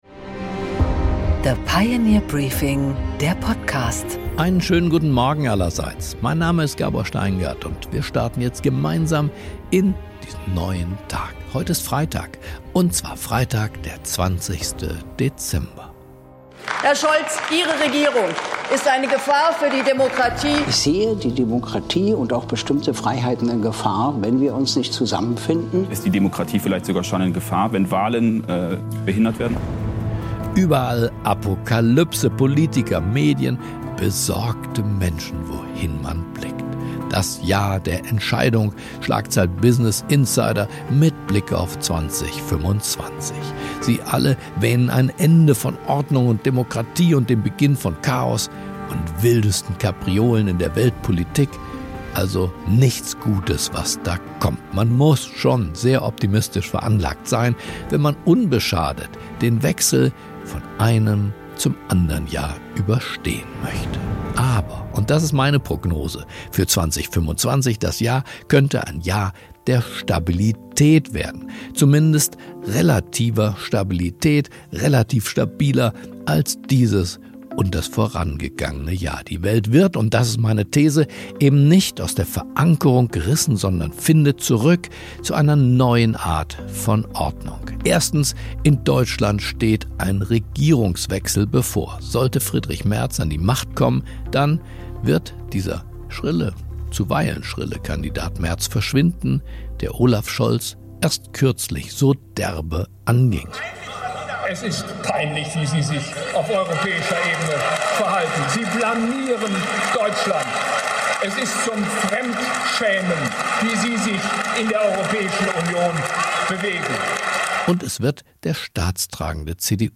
Gabor Steingart präsentiert das Pioneer Briefing